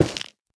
1.21.5 / assets / minecraft / sounds / block / stem / break3.ogg
break3.ogg